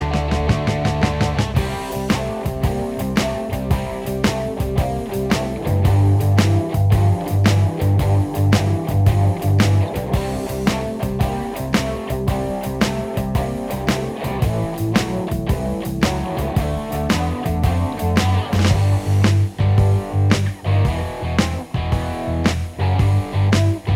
Minus Guitars Soft Rock 4:25 Buy £1.50